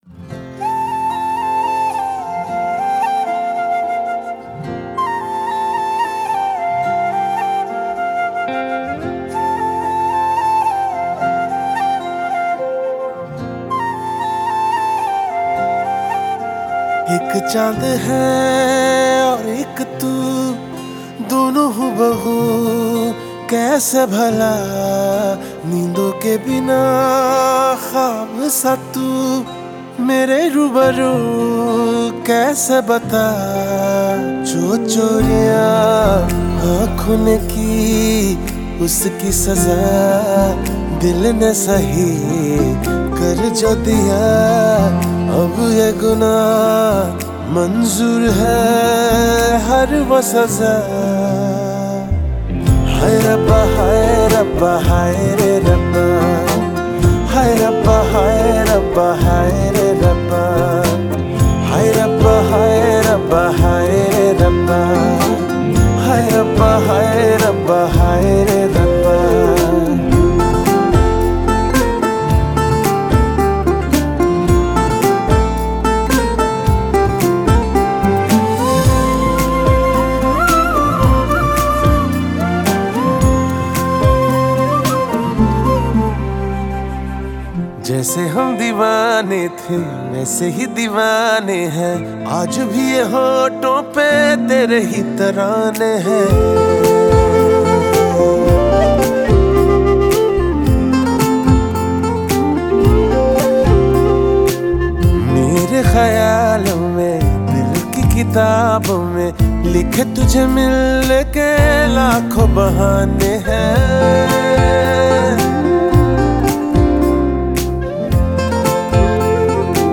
2020 Pop Mp3 Songs